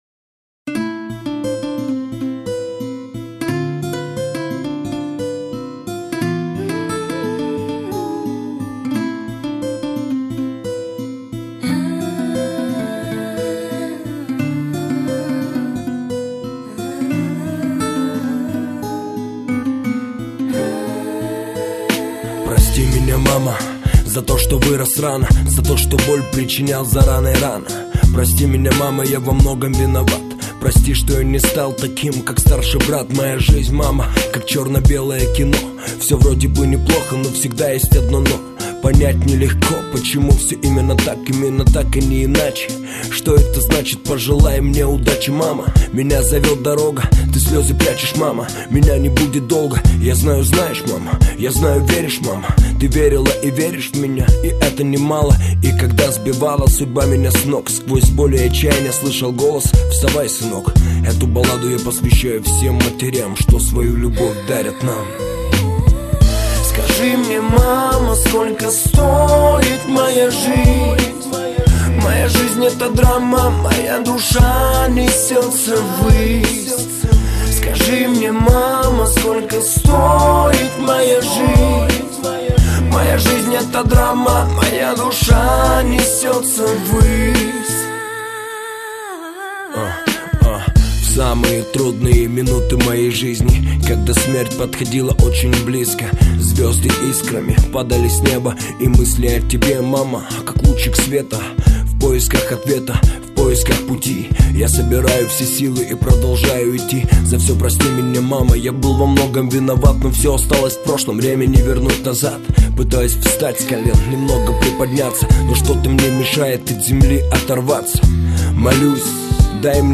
Русский рэп